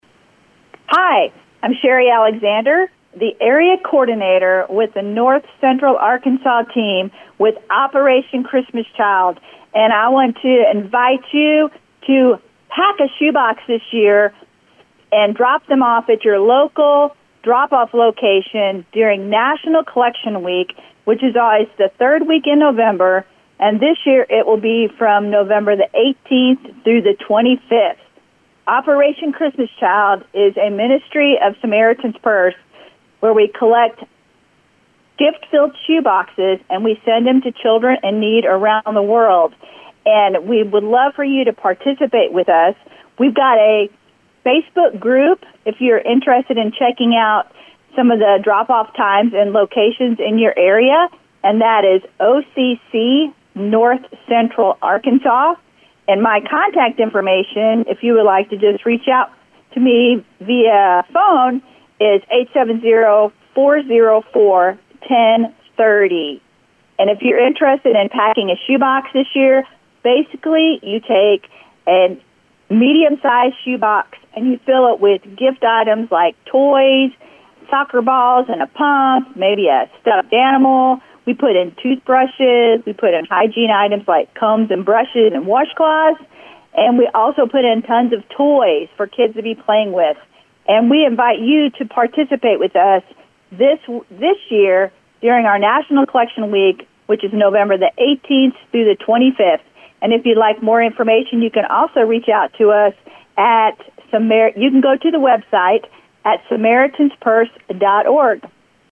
talked to Ozark Radio News to help spread the word and explain how collection week works.